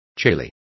Complete with pronunciation of the translation of chile.